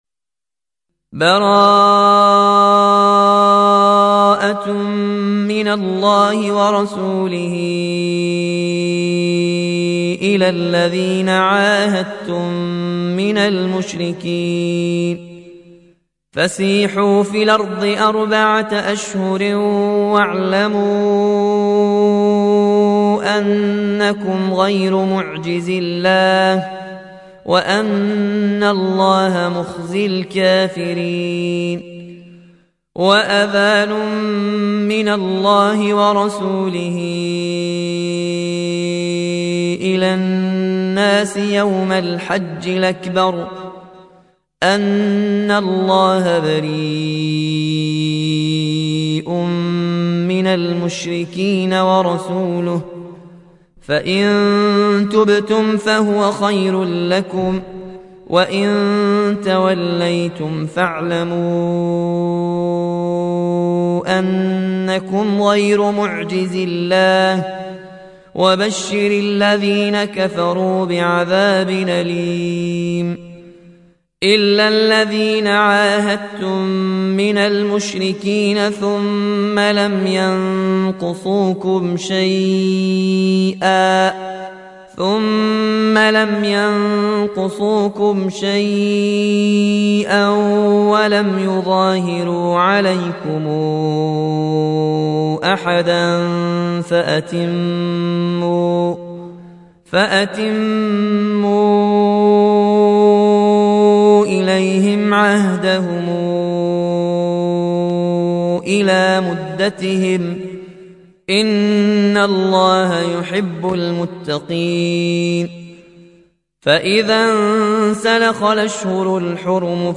(روایت ورش)